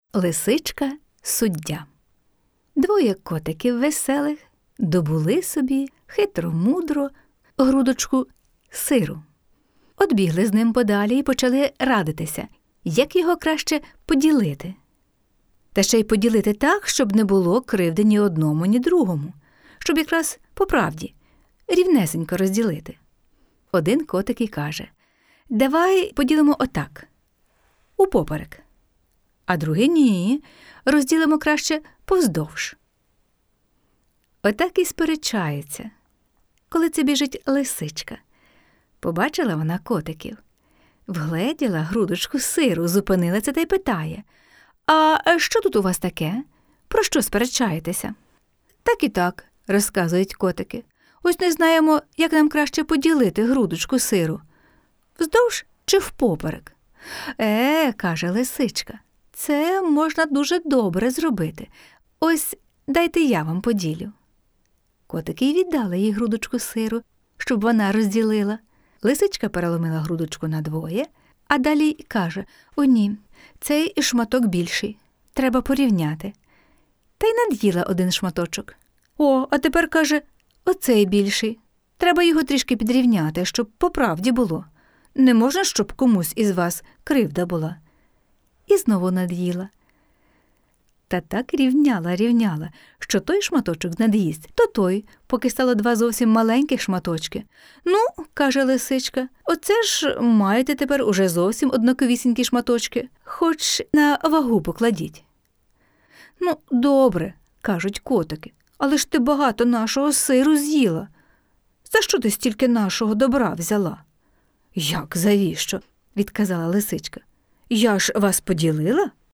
Казка "Лисичка-суддя" - слухати онлайн